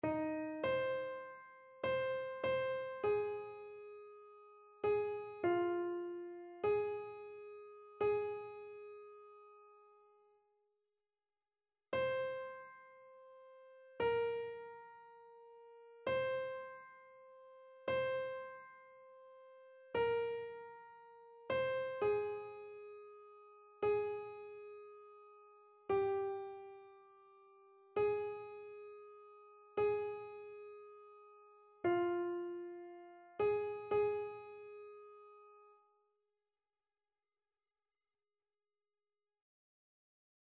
Chœur